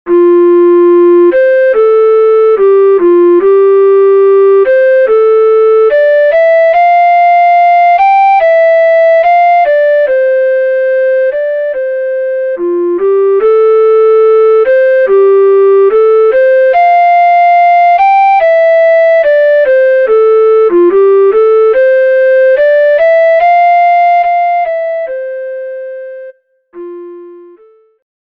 Alto
This composition is meant to be played energetically and expressively.
An easy and relaxing solo for alto recorder, with chords provided for a friend on guitar or piano (or harp!). Lots of low F's keep the piece grounded, and it never goes above the upper Bb.